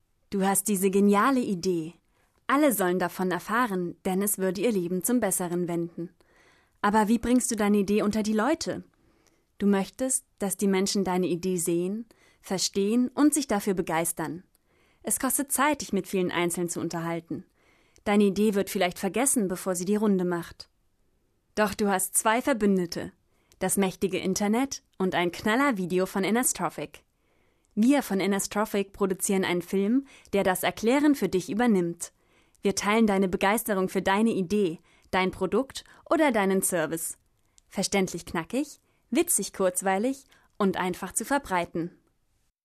Authentische, von jung frisch frech über verträumt sinnlich bis dynamisch seriöse Stimme.
Sprechprobe: Industrie (Muttersprache):
Female voice over artist German/English. Authentic voice, from young and fresh to sleepy sensual and dynamic respectable.